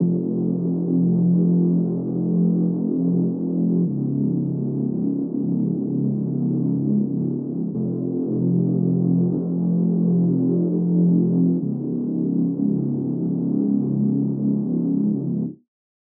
AV_Mythology_Bass_124bpm_Fmin.wav